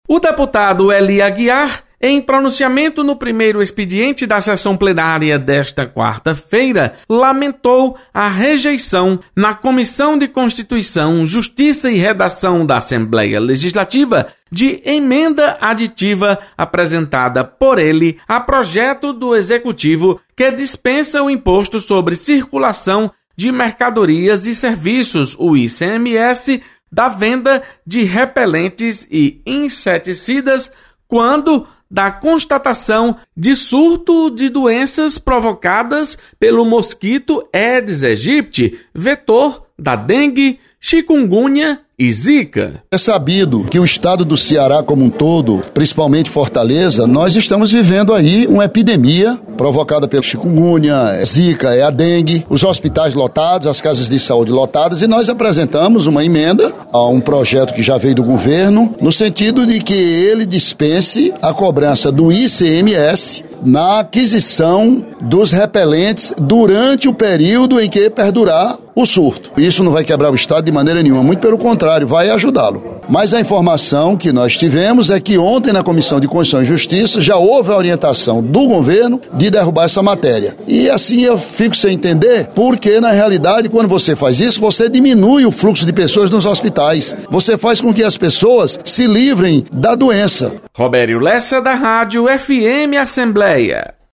Deputado Ely Aguiar defende redução de ICMS para repelentes a fim de ajudar no combate a doenças transmitidas pelo mosquito Aedes aegypti.. Repórter